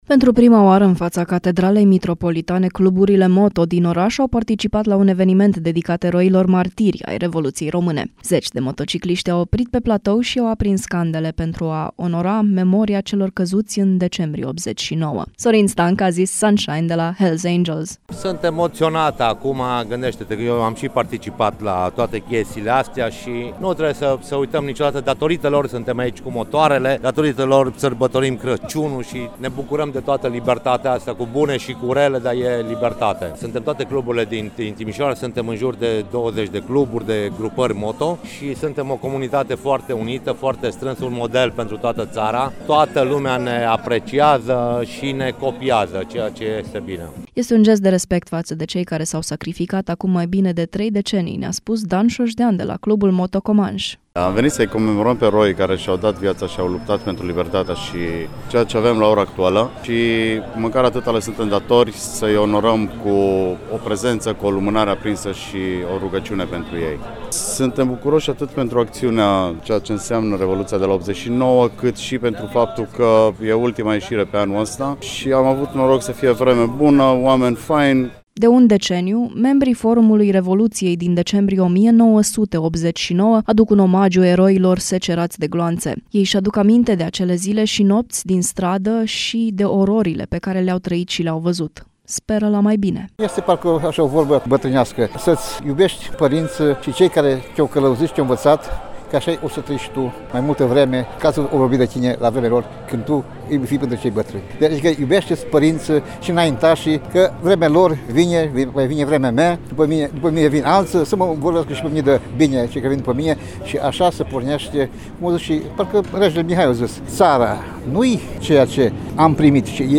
Zeci de candele au fost aprinse duminică seara, în fața Catedralei Mitropolitane, în memoria eroilor martiri ai Revoluției din decembrie 1989.